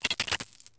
CoinsRemoveSound.wav